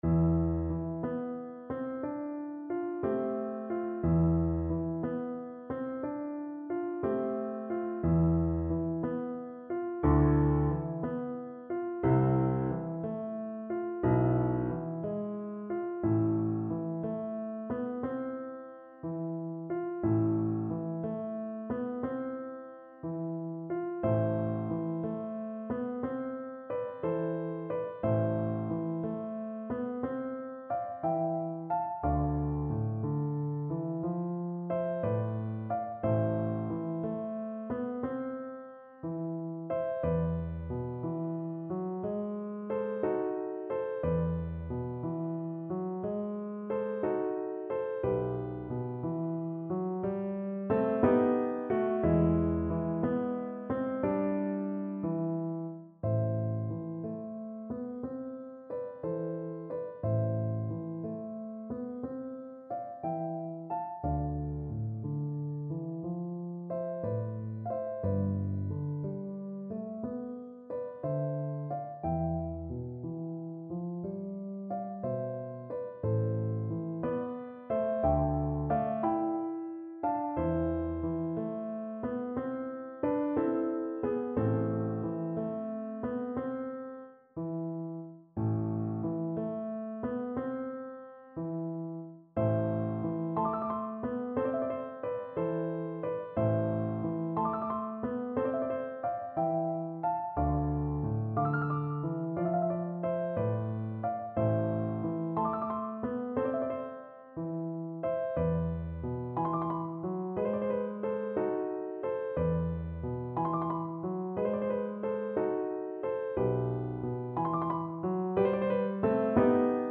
6/8 (View more 6/8 Music)
Andante (.=60)
Classical (View more Classical Flute Music)